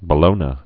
(bə-lōnə)